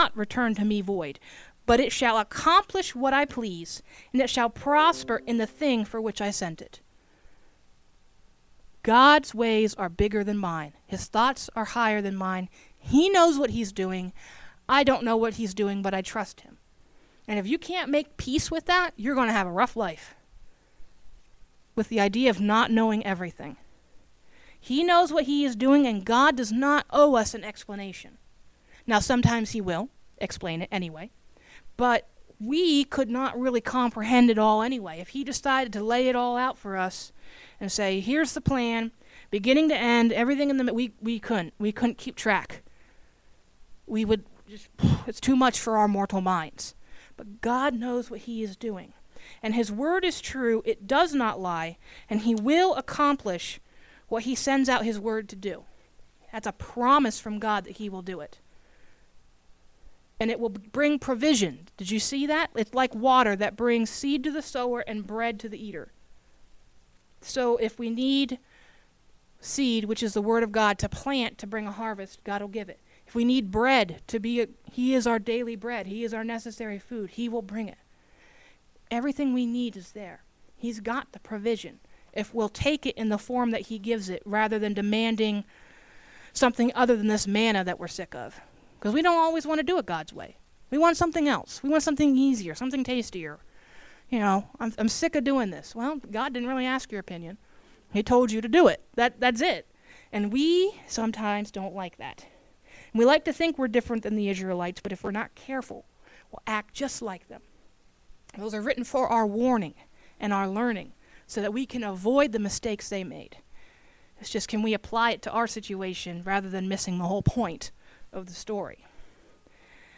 Aug 02, 2020 Warring With Our Prophecies MP3 SUBSCRIBE on iTunes(Podcast) Notes Discussion Unfortunately, the beginning of this sermon was not recorded.